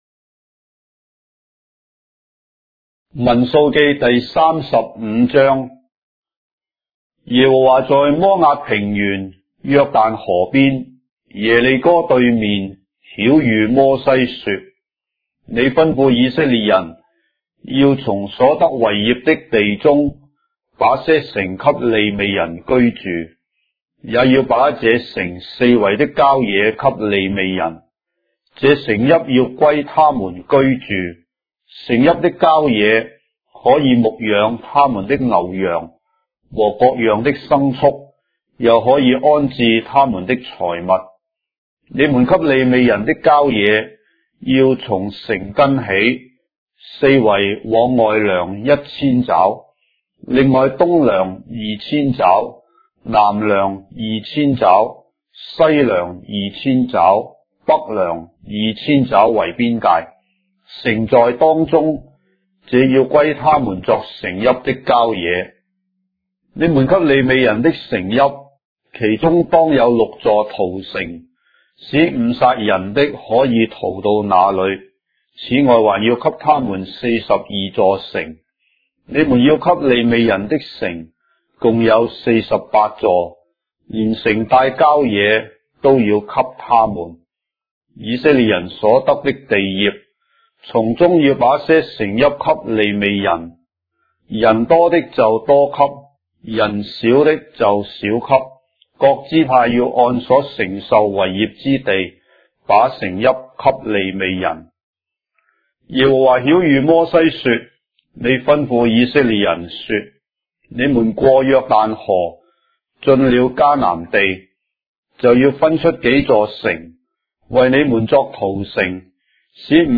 章的聖經在中國的語言，音頻旁白- Numbers, chapter 35 of the Holy Bible in Traditional Chinese